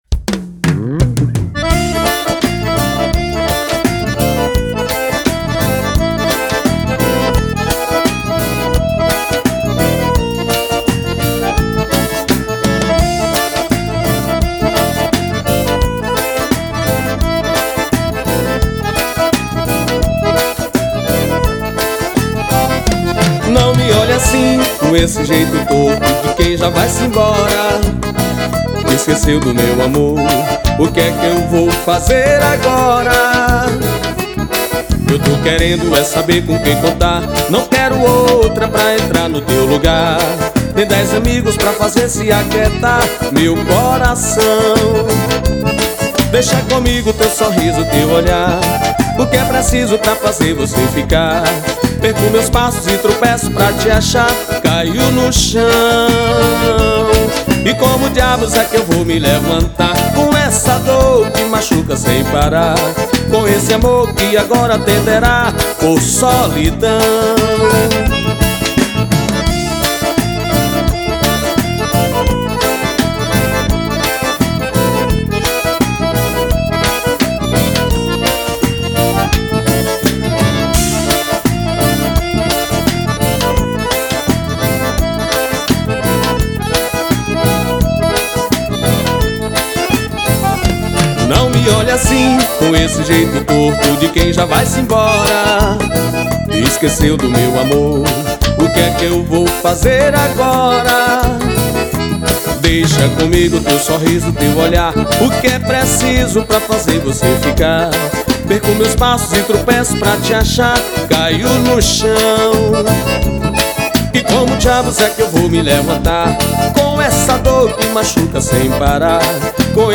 2533   02:41:00   Faixa: 12    Baião